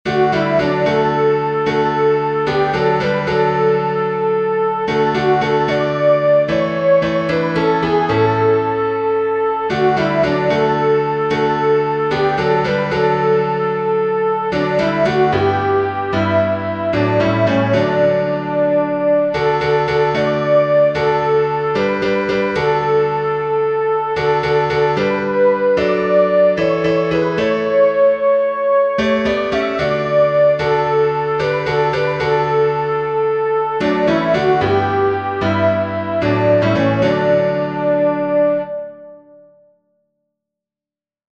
blessed_assurance-openhymnal-soprano.mp3